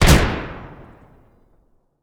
rifle3.wav